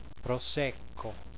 Italian Wine Pronunciation Guide
Click on a speaker symbol to hear the word spoken aloud.